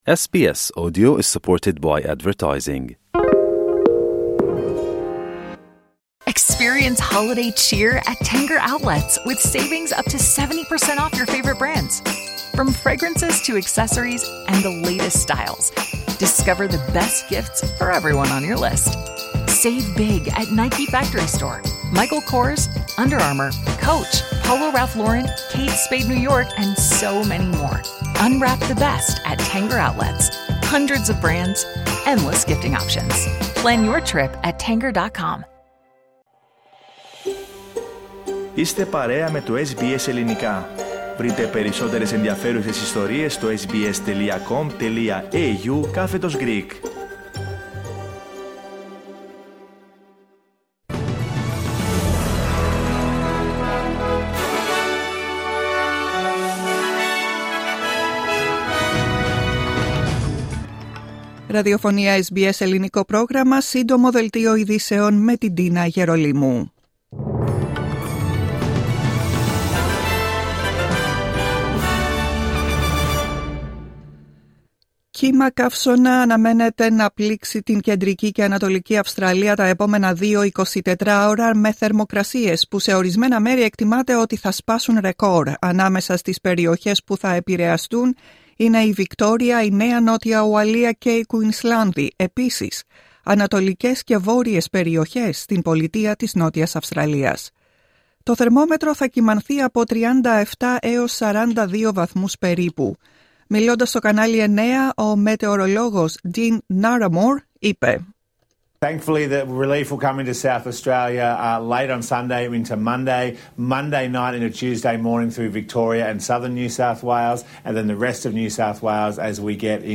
Δελτίο ειδήσεων Σάββατο 14 Δεκεμβρίου 2024